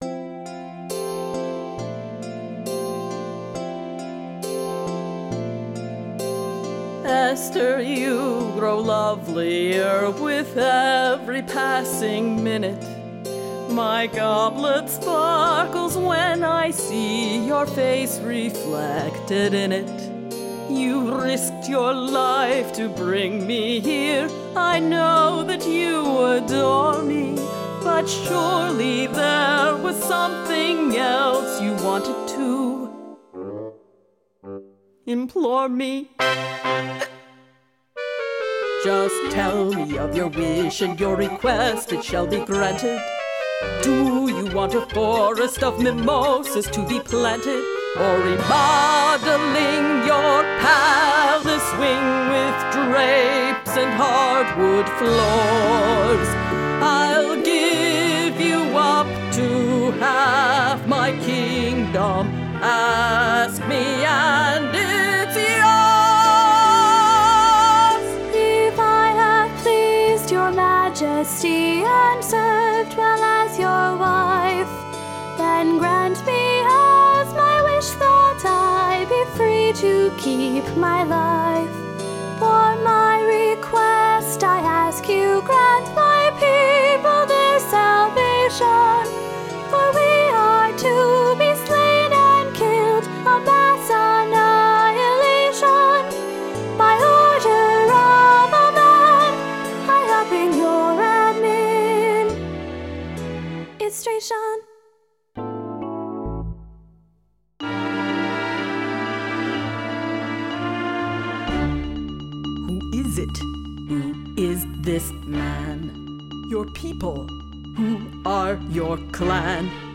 The world premier production of this original opera by NCFO founder David Bass was performed at the King Open School, 850 Cambridge St, Cambridge, MA in late March, 2004.